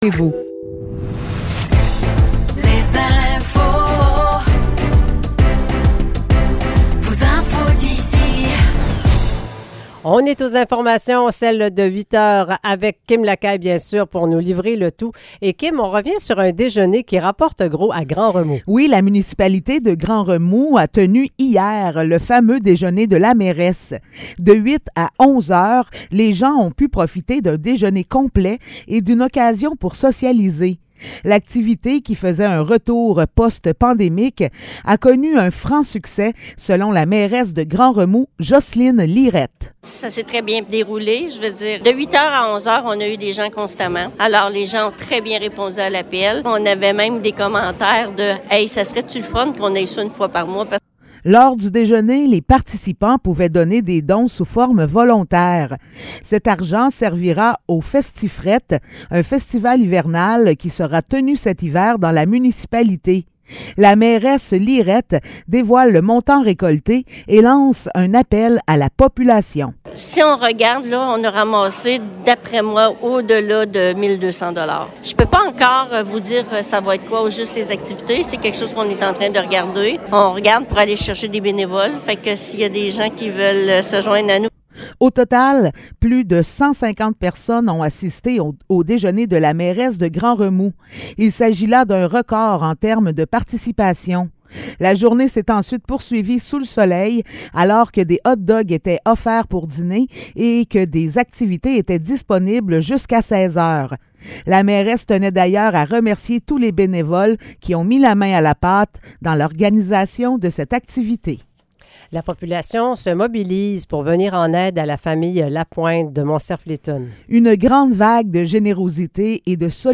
Nouvelles locales - 25 septembre 2023 - 8 h